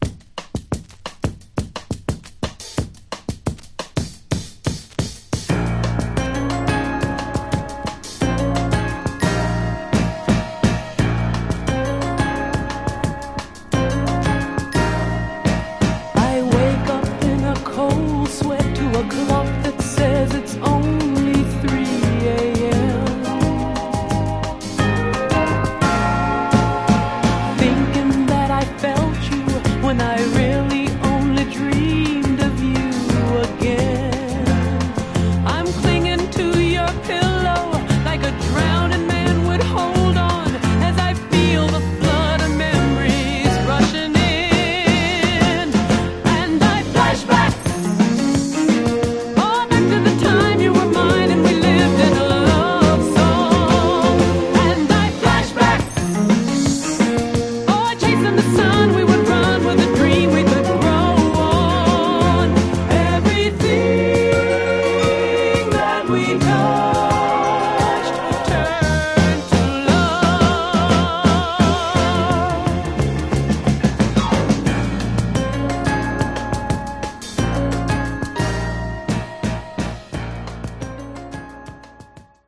Genre: Northern Soul, Philly Style